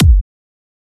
edm-kick-42.wav